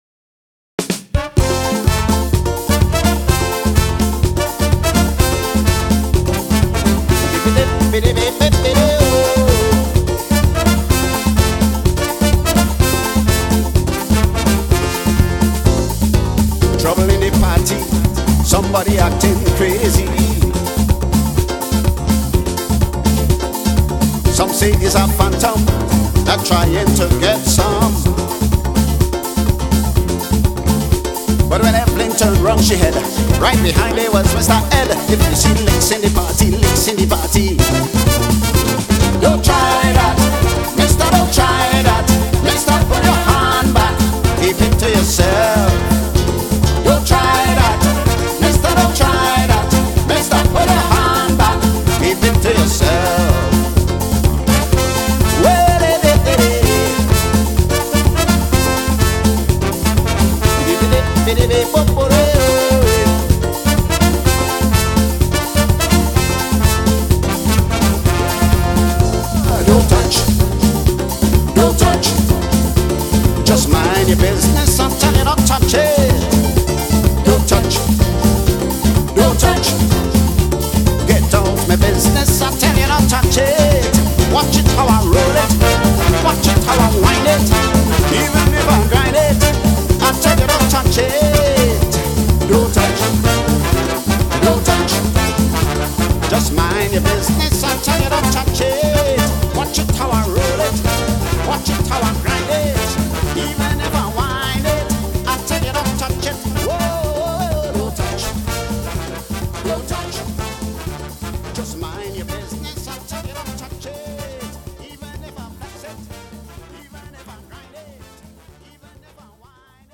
TThe Trio: Bass, Guitar & Drums, self-contained vocals.
Soca, Calypso, Reggae, Compa, Zouk and African music.